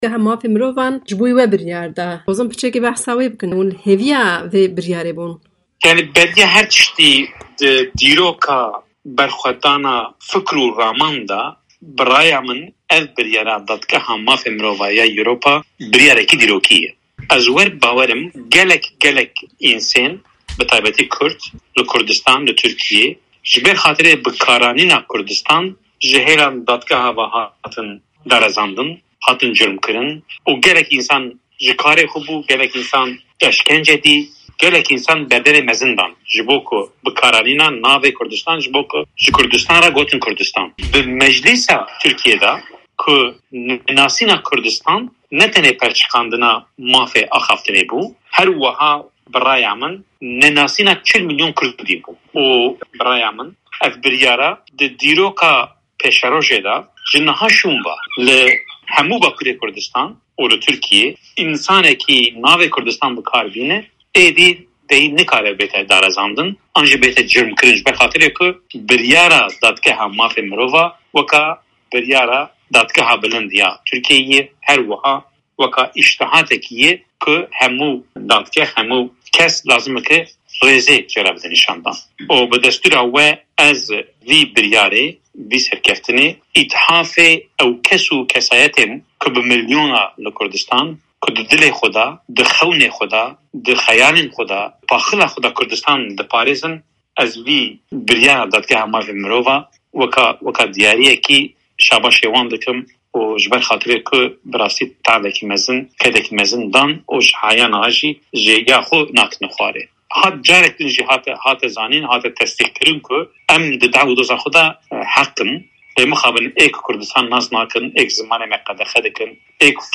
Parlamenterê berê yê bajarê Ruhayê Osman Baydemîr di hevpeyvînekê de ligel Dengê Amerîka helwesta xwe hember biryara Dadgeha Mafên Mirovan ya Ewrupî (ECHR) derbarê doza wî nîşan da.